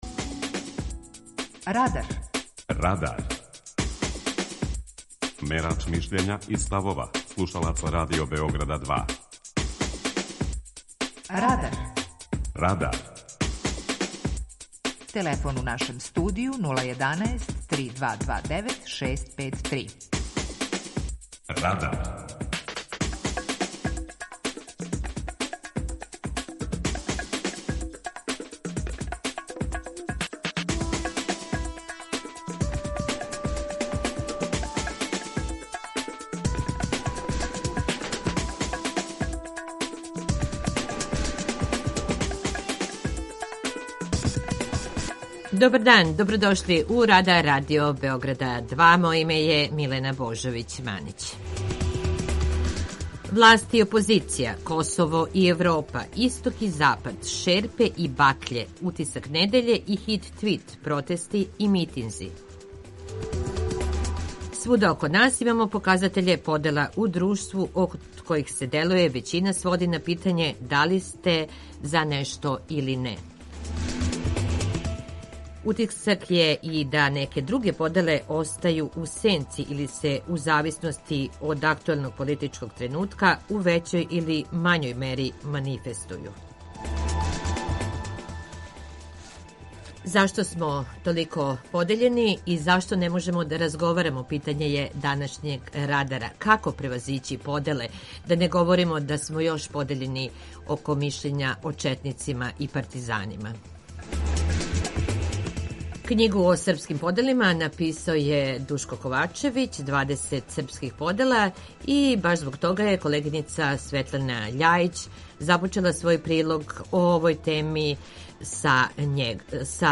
Питање Радара: Како превазићи поделе у српском народу? преузми : 18.97 MB Радар Autor: Група аутора У емисији „Радар", гости и слушаоци разговарају о актуелним темама из друштвеног и културног живота.